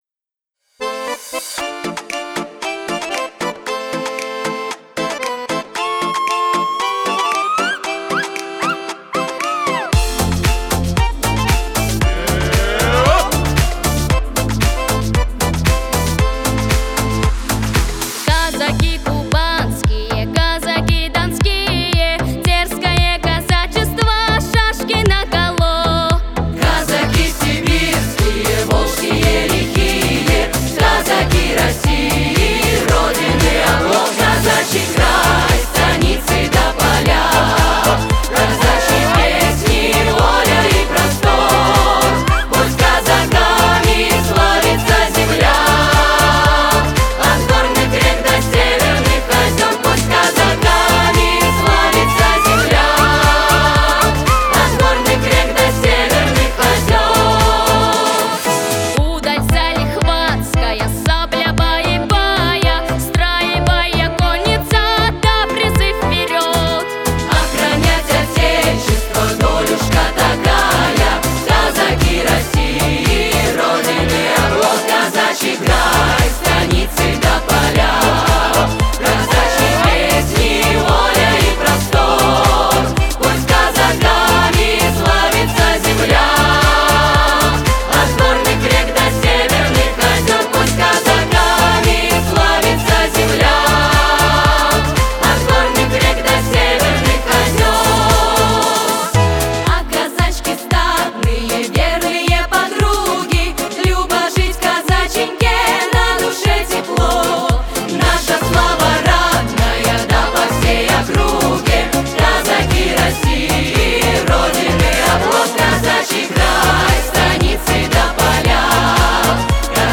• Категория: Детские песни
казачья
патриотическая